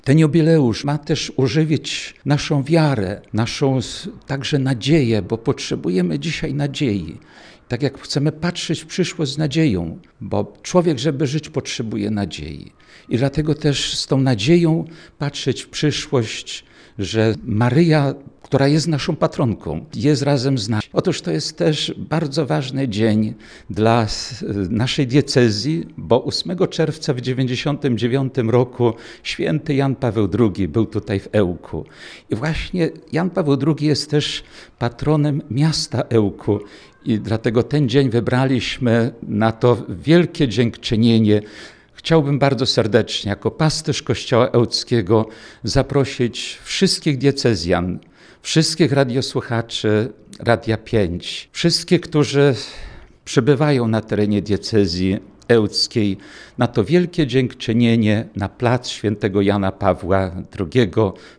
-Wtedy to na placu Jana Pawła II w Ełku zostanie odprawiona msza święta dziękczynna za 25 lat, mówi ks. Jerzy Mazur, Biskup Diecezji Ełckiej